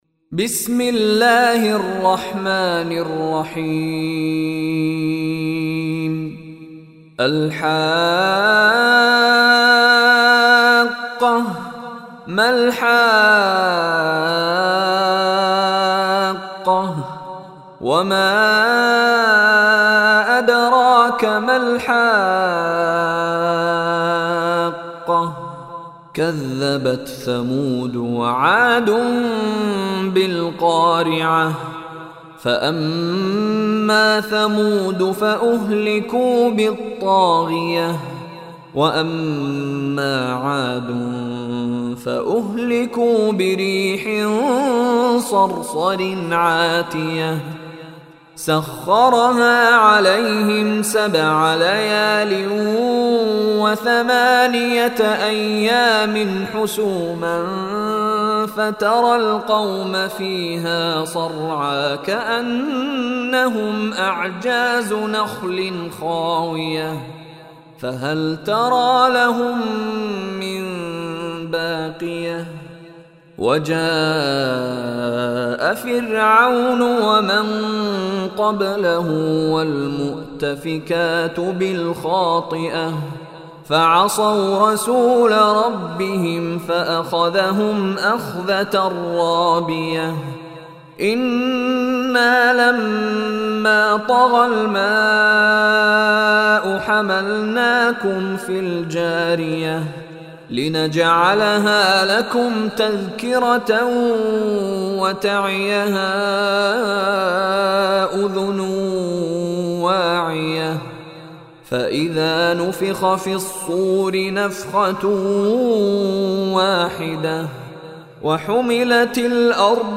Surah Haqqah Recitation by Mishary Rashid
Download audio recitation Surah Haqqah in the beautiful voice of Sheikh Mishary Rashid Alafasy.